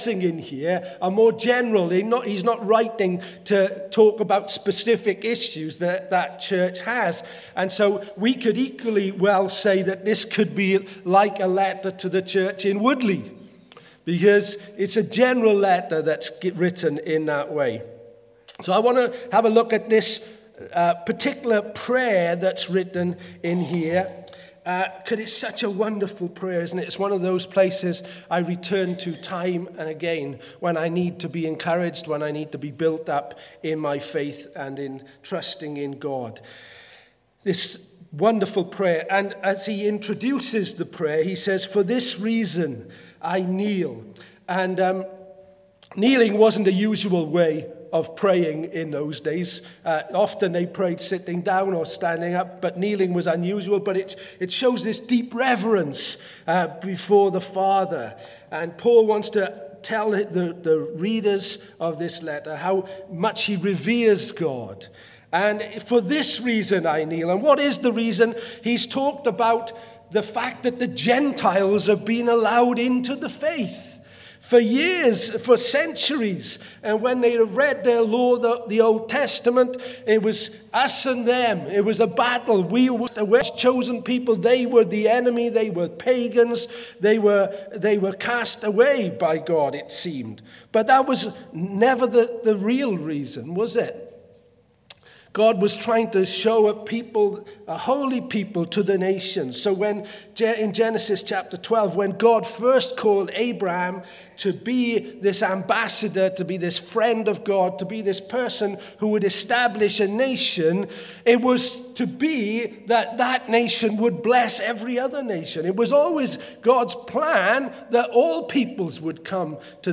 Bible Text: Ephesians 3:14-21 | Preacher